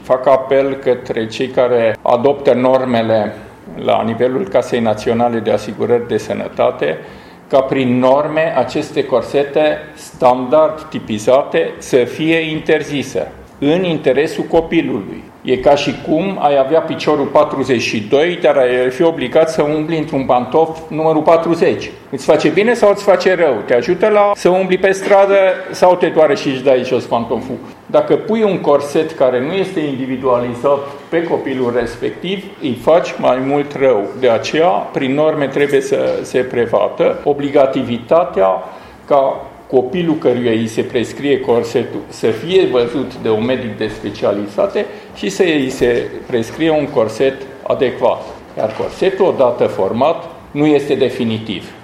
El a declarat astăzi la Tîrgu-Mureș, în cadrul Taberei de Scolioză ScolioCamp, că tratamentul clasic și decontat de Casa de Asigurări de Sănătate ar trebui regândit, pentru mai bune rezultate pentru bolnavi.
György Frunda a arătat că ar trebui modificate normele referitoare la decontarea acestor corsete: